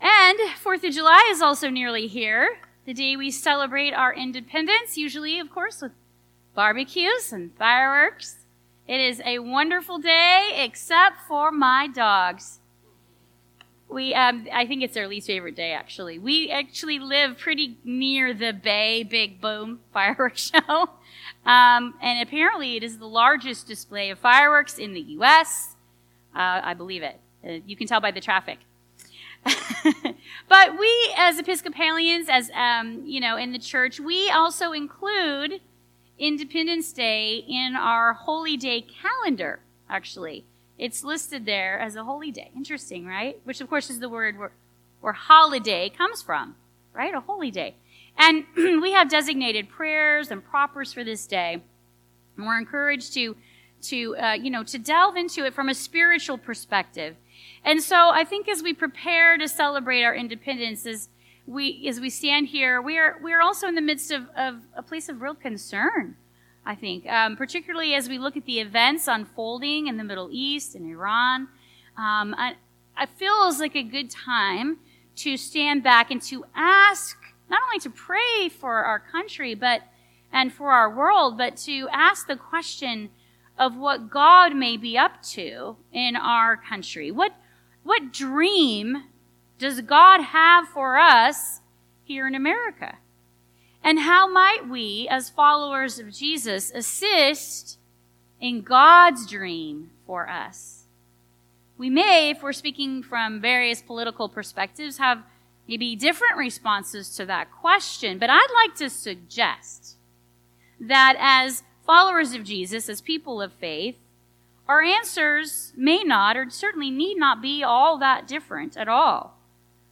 Listen to our recorded sermons in high-quality .mp3 format.